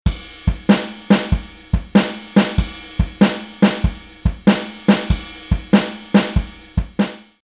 Comment: Jazz drum beat pattern.